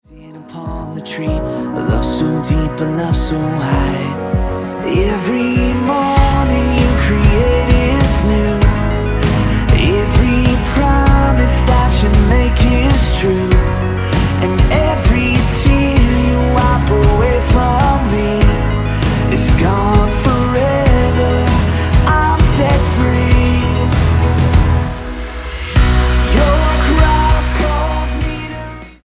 STYLE: Pop
an atmospheric song of praise